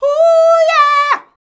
HUUYEAH L.wav